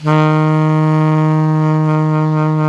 Index of /90_sSampleCDs/Roland L-CDX-03 Disk 1/SAX_Alto Tube/SAX_Alto mp Tube
SAX ALTOMP01.wav